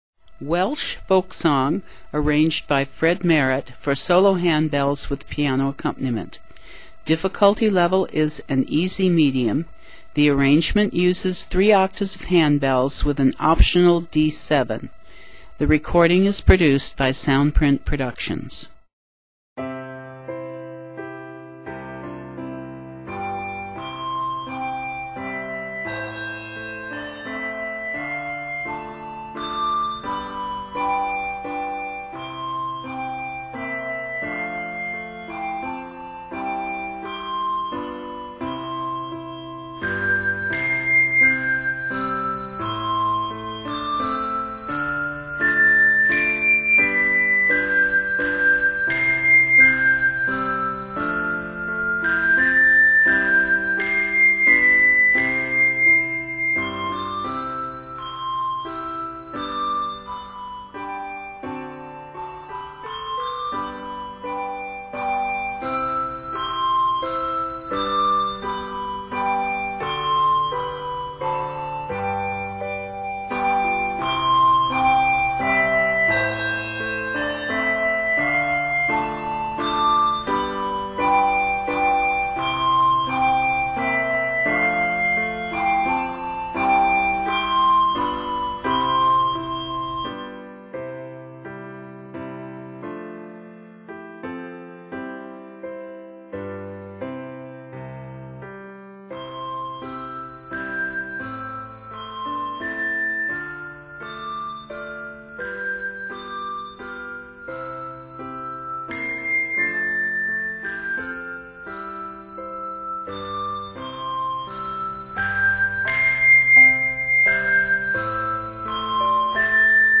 is a slow waltz with a lovely melody
Set in C Major, measures total 75.
Solo Ringer
Technique: Sk (Shake) Voicing: Handbells
Instrument: Piano